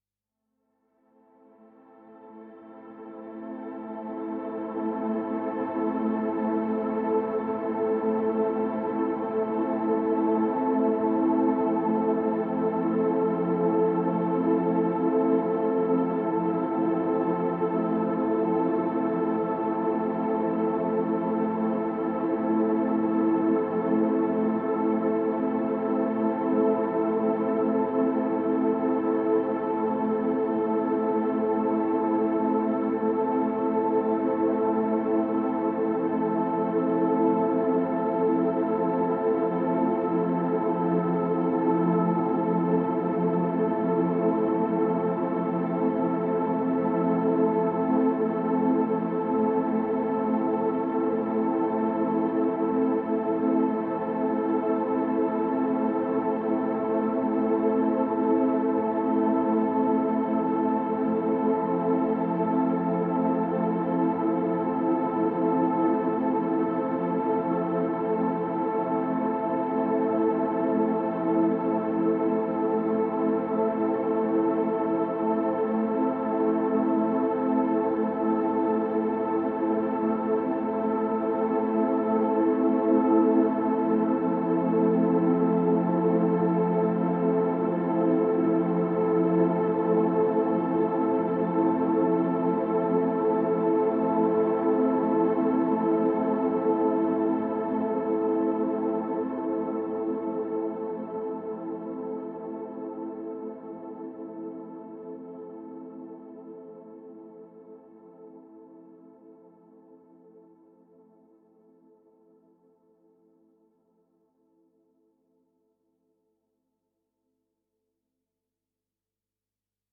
Airy pads gently float to form a reflective ambience.